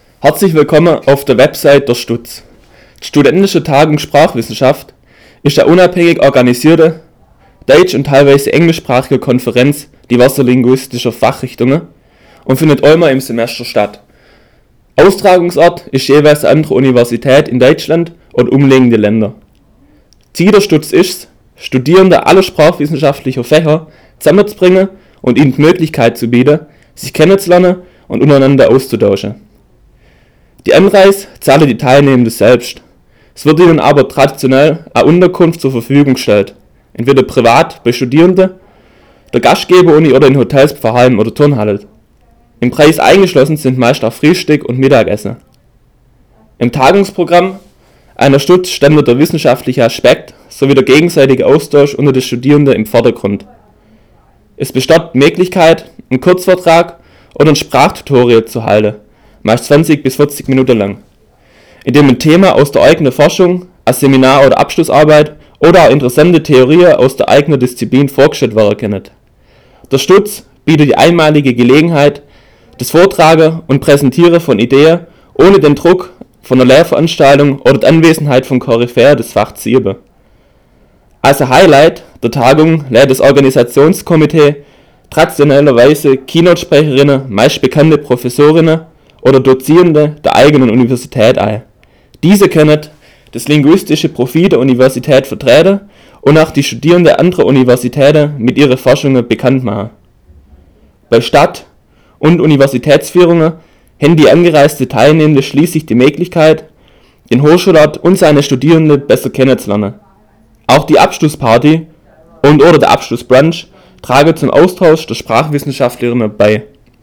Studentische Tagung Sprachwissenschaft | Schwäbisch
Die StuTS auf Schwäbisch